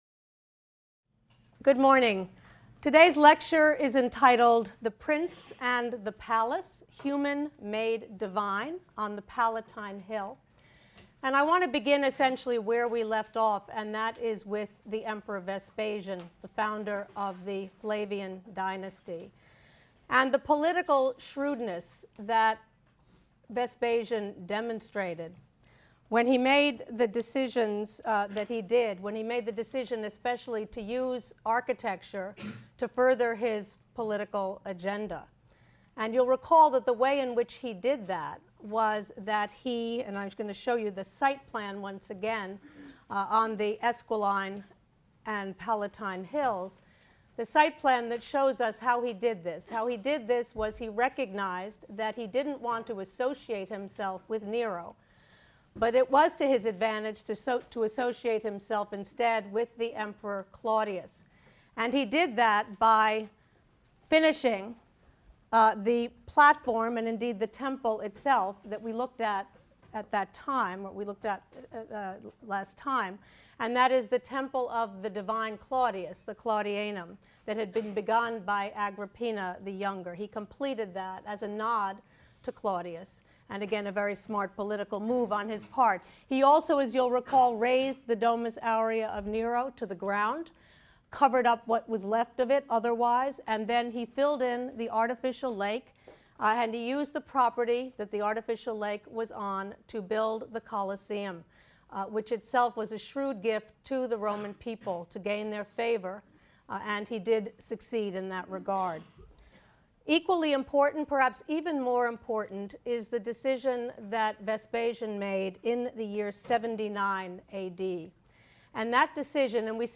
HSAR 252 - Lecture 13 - The Prince and the Palace: Human Made Divine on the Palatine Hill | Open Yale Courses